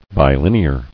[bi·lin·e·ar]